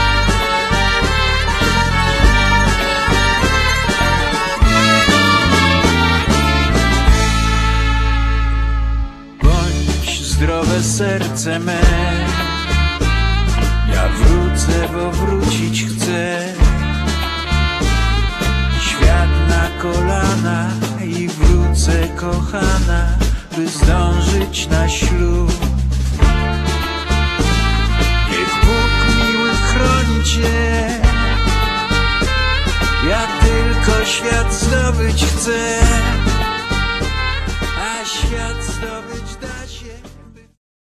gitara basowa, kontrabas, piła
perkusja
akordeon
trąbka, flugelhorn
gitara, mandolina